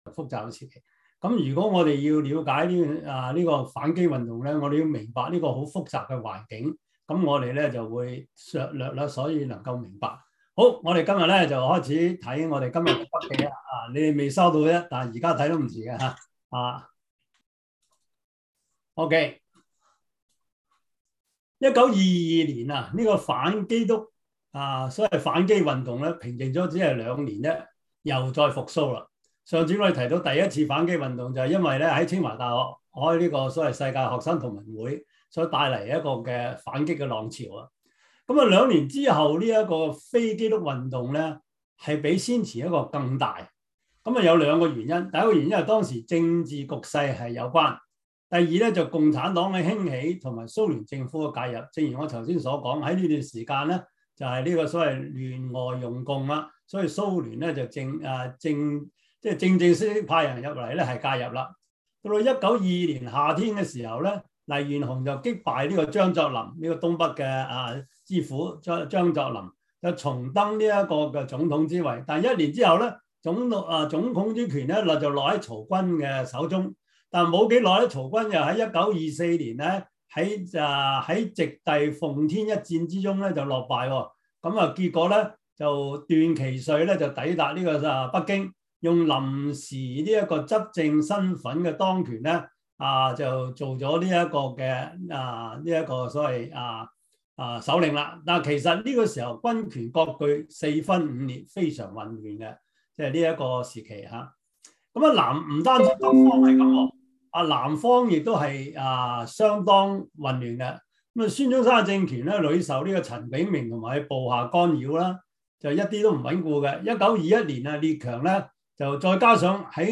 Series: 中文主日學